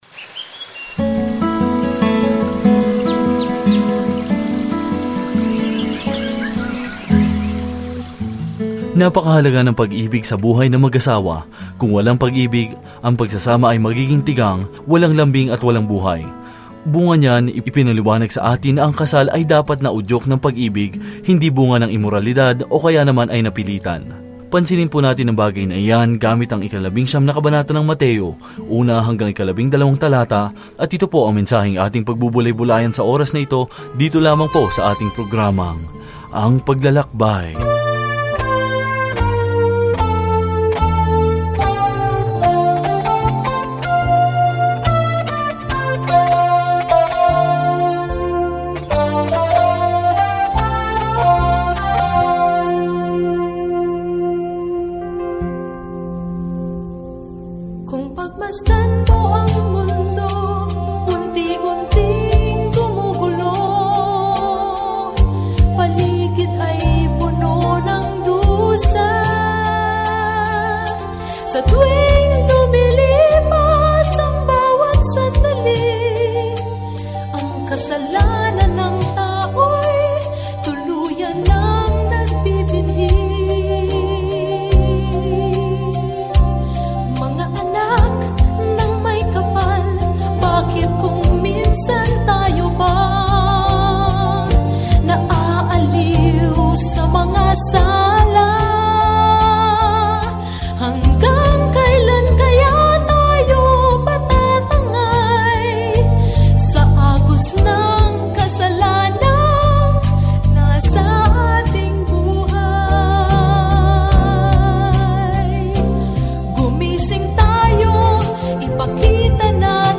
Banal na Kasulatan Mateo 19:1-15 Araw 26 Umpisahan ang Gabay na Ito Araw 28 Tungkol sa Gabay na ito Pinatunayan ni Mateo sa mga Judiong mambabasa ang mabuting balita na si Jesus ang kanilang Mesiyas sa pamamagitan ng pagpapakita kung paano natupad ng Kanyang buhay at ministeryo ang hula sa Lumang Tipan. Araw-araw na paglalakbay sa Mateo habang nakikinig ka sa audio study at nagbabasa ng mga piling talata mula sa salita ng Diyos.